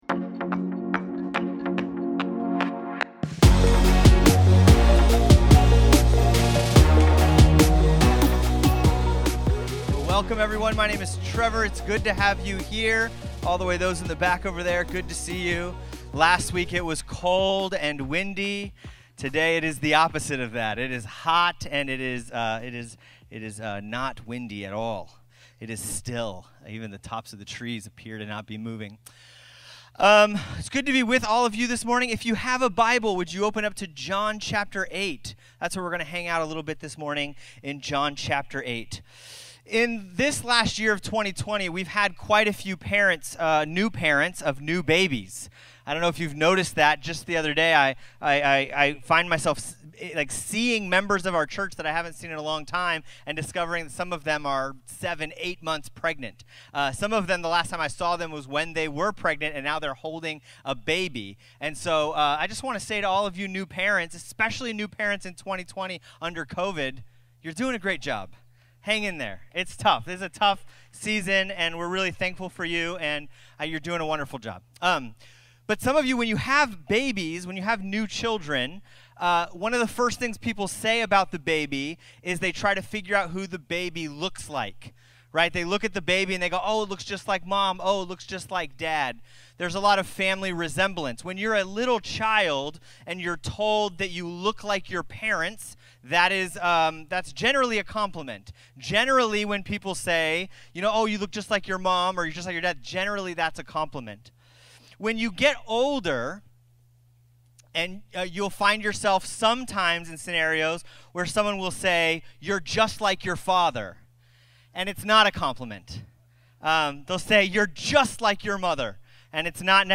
Sermons | RISEN CHURCH SANTA MONICA, INC